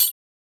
Lunch77 Perc 4.wav